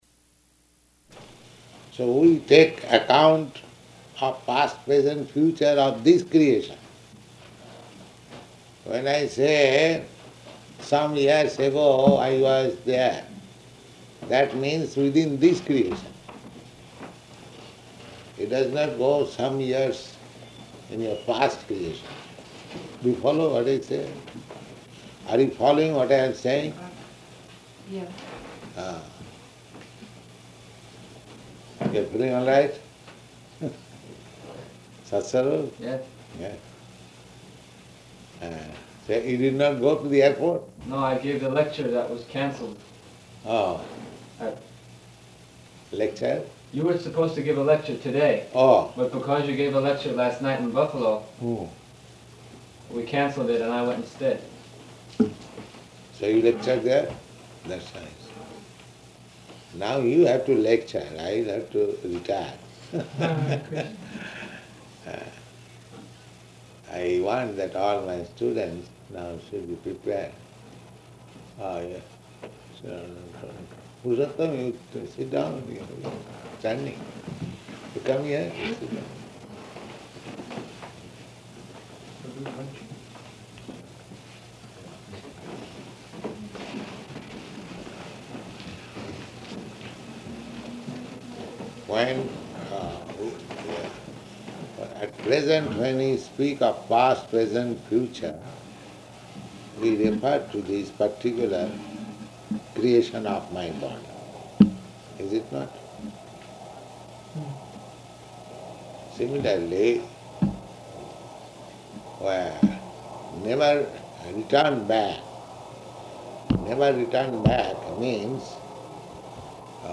Room Conversation
Room Conversation --:-- --:-- Type: Conversation Dated: April 24th 1969 Location: Boston Audio file: 690424R3-BOSTON.mp3 Prabhupāda: So we take account of past, present, future of this creation.